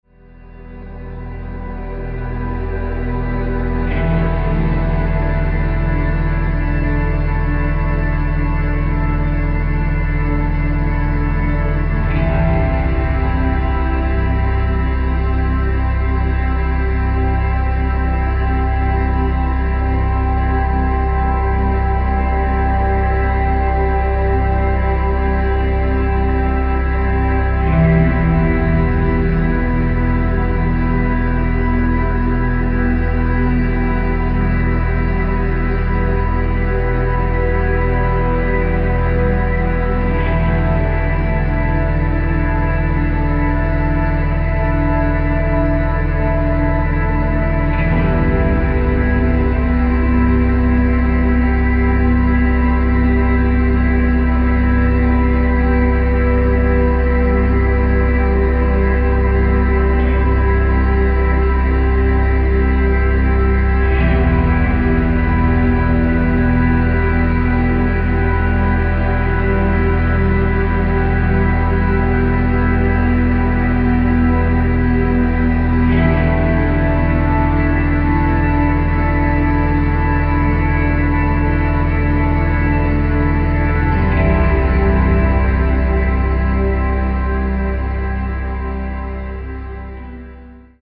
ambient-drone duo